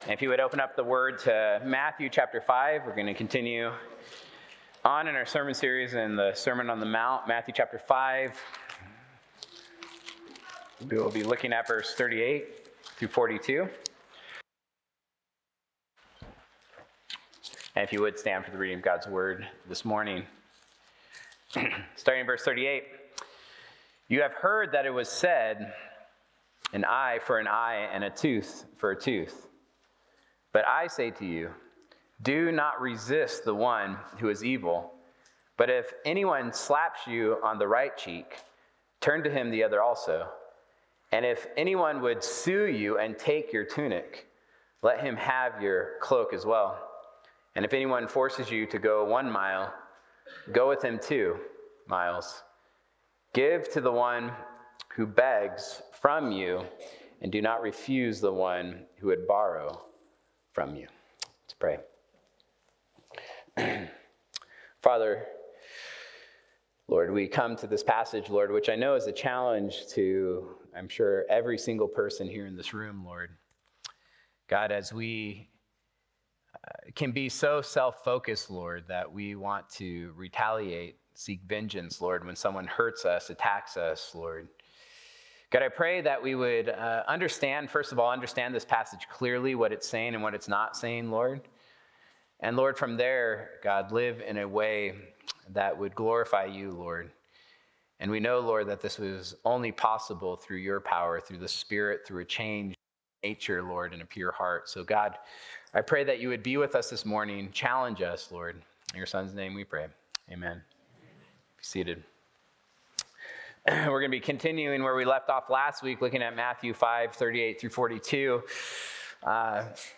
Sunday-Sermon-November-2-2025.mp3